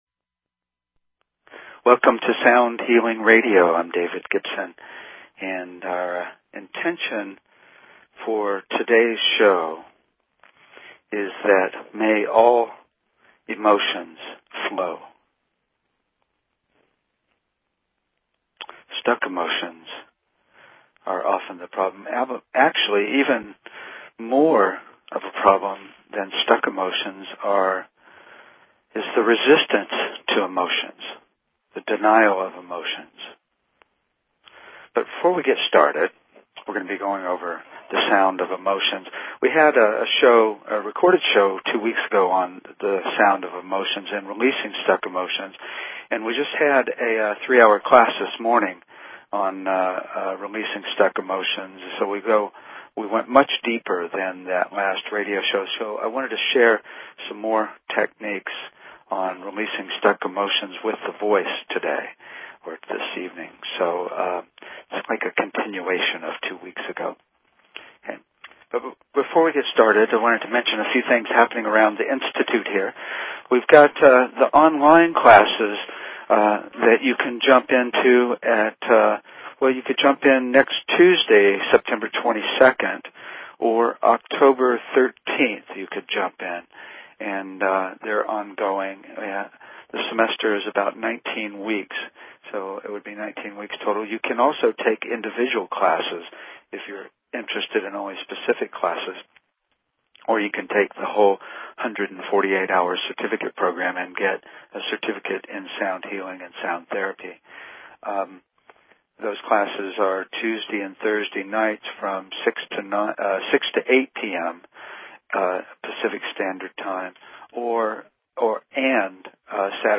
Talk Show Episode, Audio Podcast, Sound_Healing and Courtesy of BBS Radio on , show guests , about , categorized as
We end with a meditation on the sound of Joy!